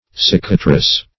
\Cic"a*trice\